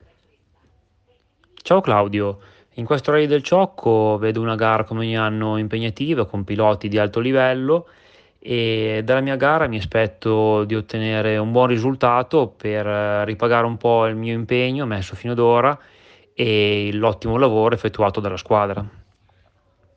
Interviste pre-gara